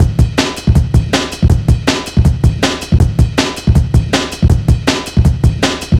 Index of /90_sSampleCDs/Zero-G - Total Drum Bass/Drumloops - 1/track 11 (160bpm)